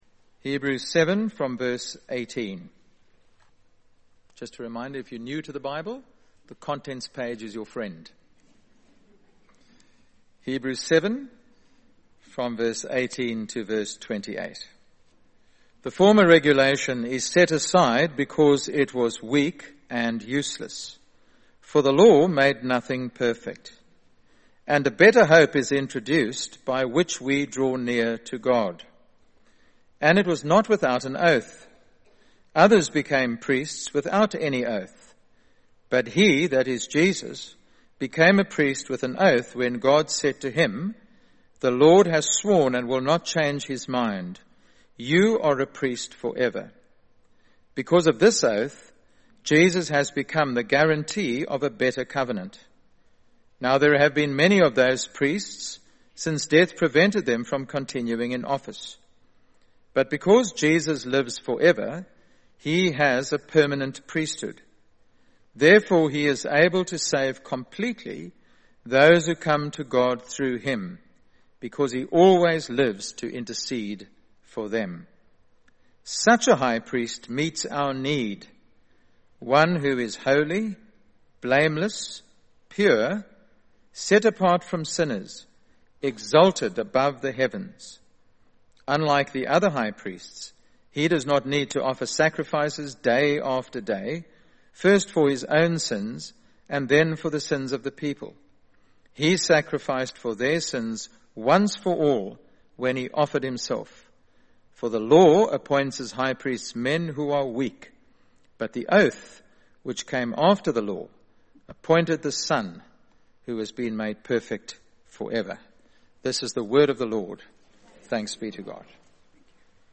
Ascension Day Service: Able to save (Hebrews 7:18-28)